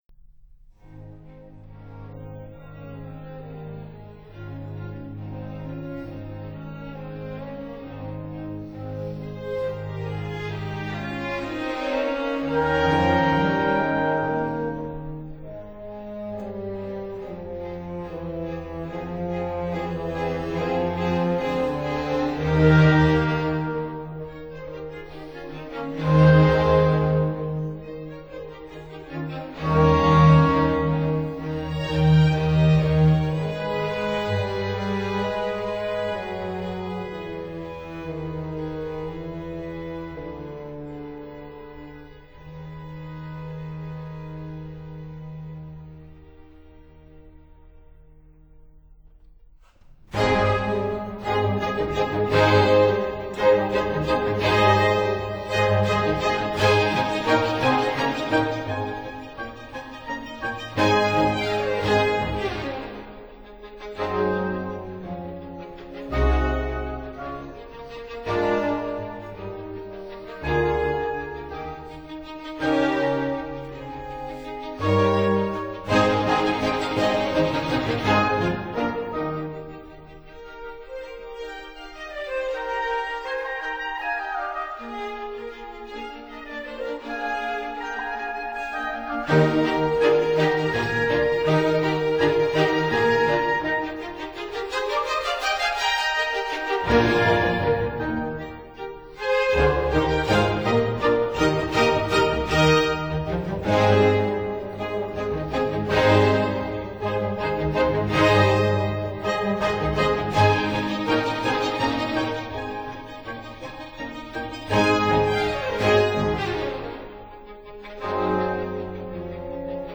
Sinfoinia in G major
Sinfoinia in B-flat major
(Period Instruments)